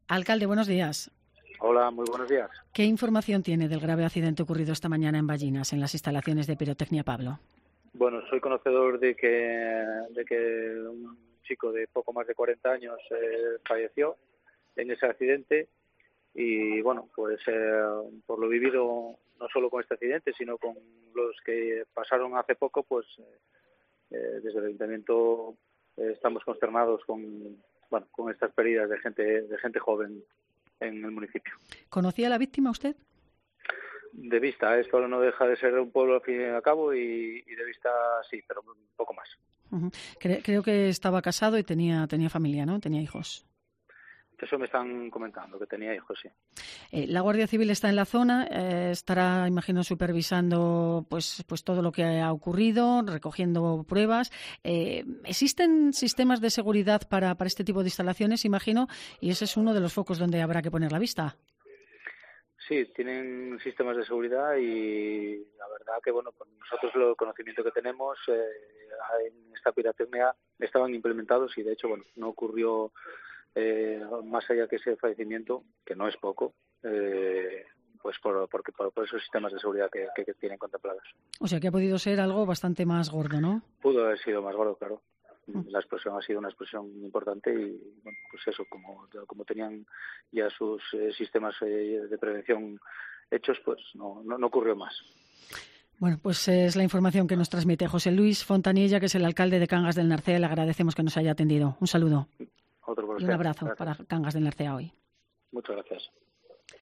Declaraciones del alcalde de Cangas del Narcea sobre la explosición en Pirotecnia Pablo
El alcalde de este concejo del suroccidente asturiano, José Luis Fontaniella, ha expresado en COPE la consternación que ha provocado el suceso en todo el municipio por la pérdida de un vecino que deja pareja e hijos.